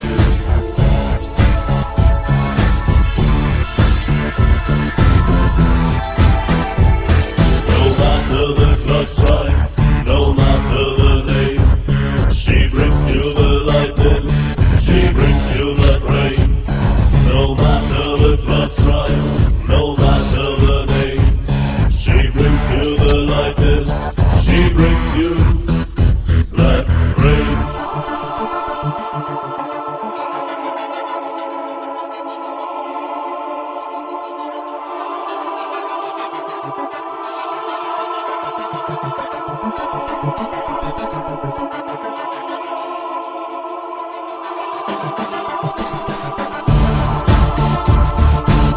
Industrial / ebm / electro / ethnodark- compilation